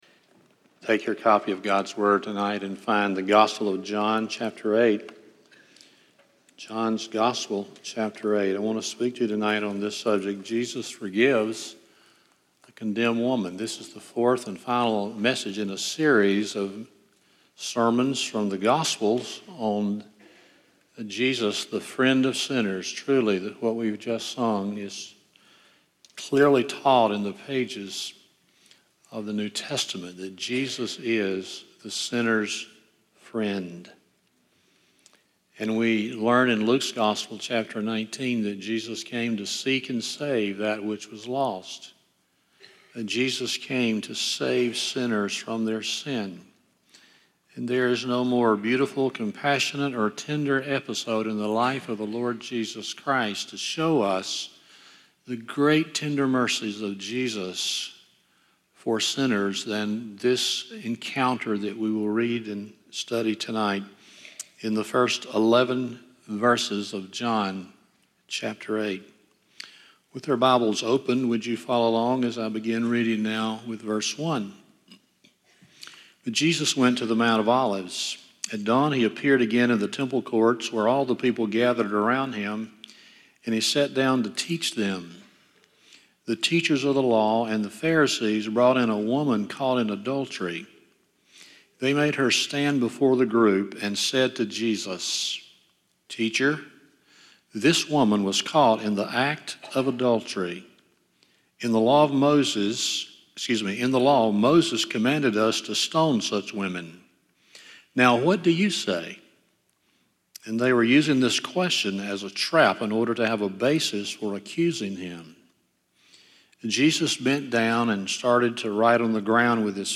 John 8:1-11 Service Type: Sunday Evening 1.